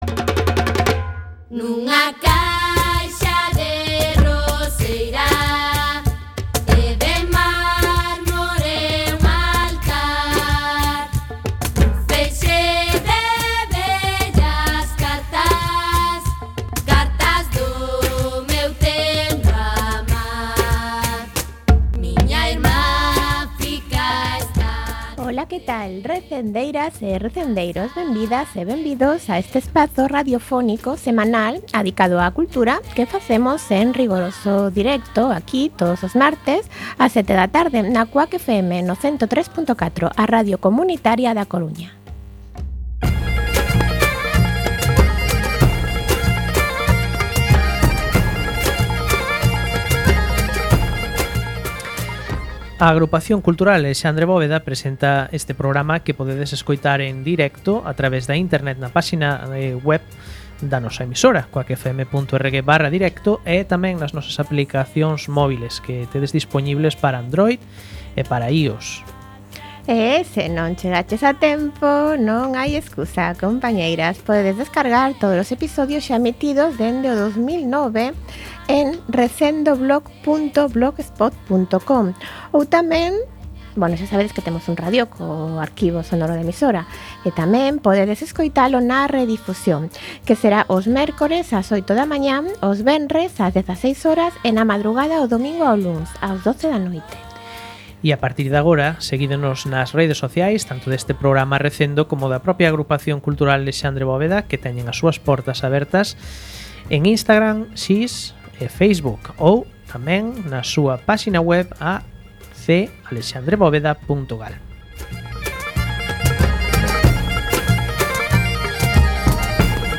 Programa número 509, no que realizamos un coloquio sobre os incendios deste verán en Galicia, no que participaron: